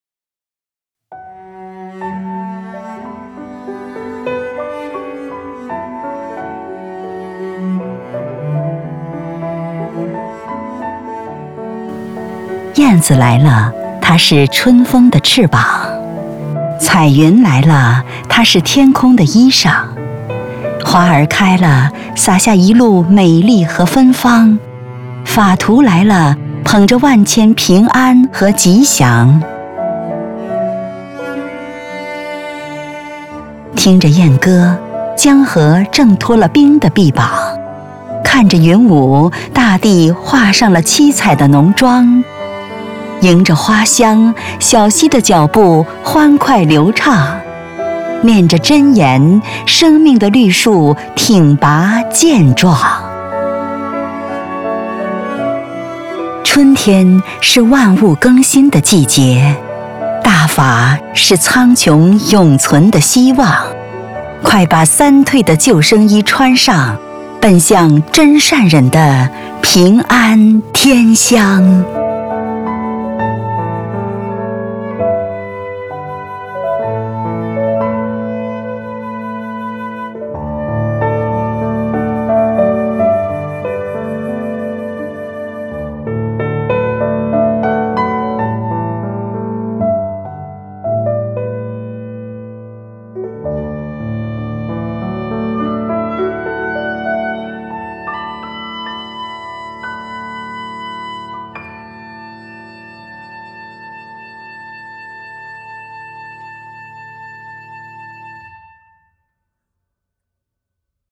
配樂詩朗誦：春曦 | 法輪大法正見網